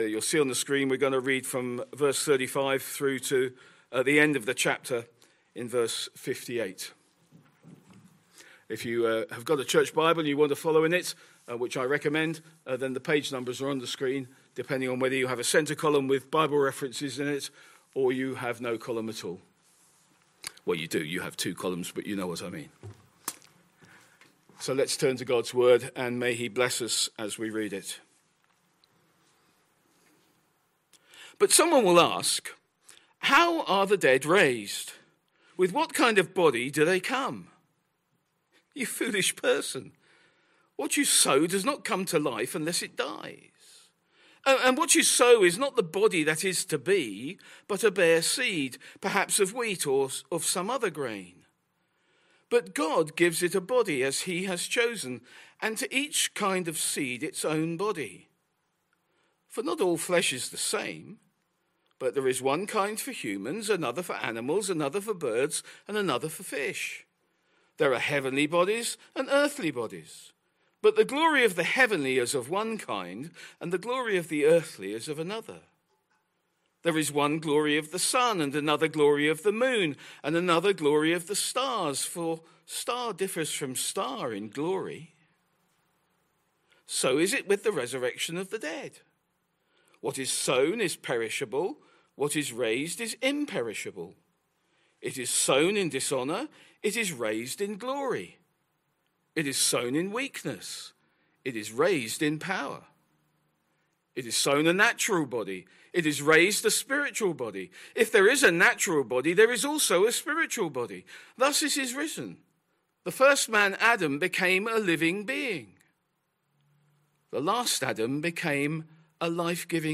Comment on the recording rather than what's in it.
Sunday PM Service Sunday 16th November 2025 Speaker